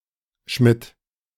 Schmid (German pronunciation: [ʃmɪt] ⓘ) is a German surname that is a cognate of "Smith", an occupational surname for a blacksmith.
De-Schmid.ogg.mp3